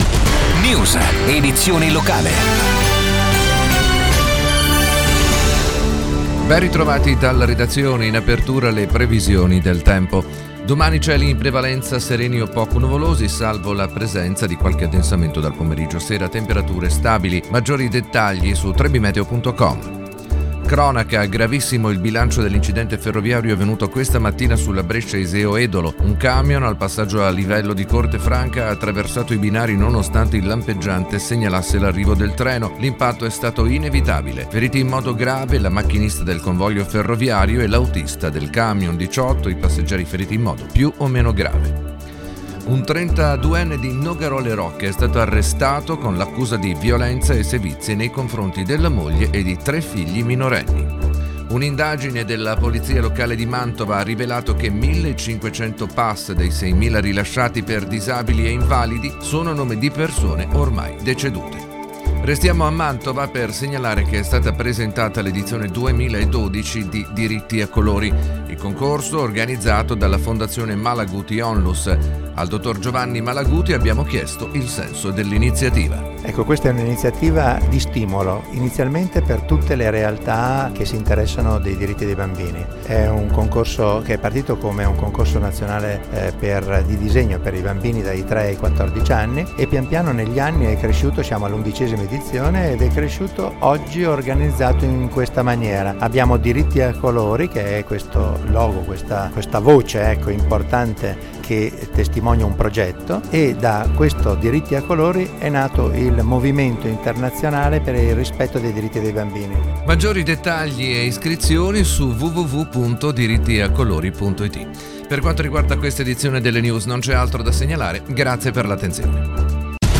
INTERVISTA RADIOFONICA A RADIO NUMBER ONE
intervista-Radio-Number-One-11-concorso-disegno-audio.mp3